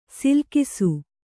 ♪ silkisu